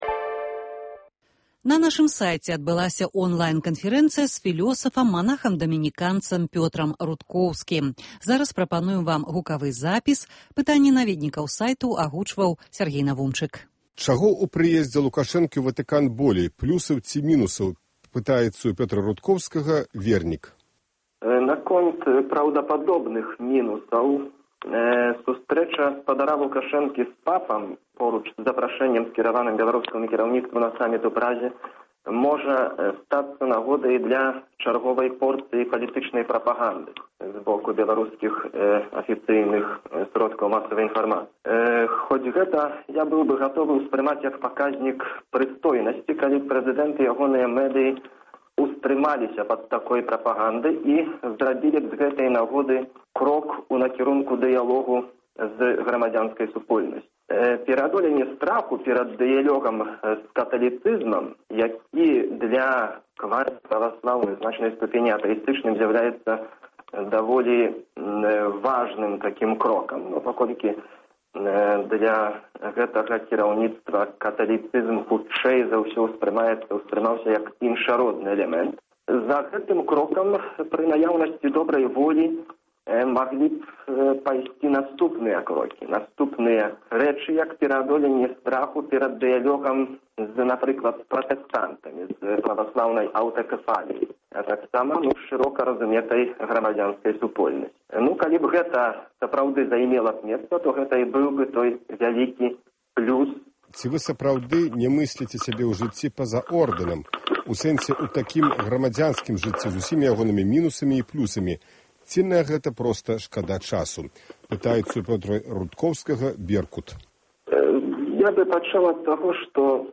Онлайн канфэрэнцыя